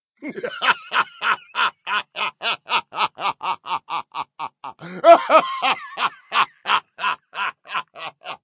laughter_01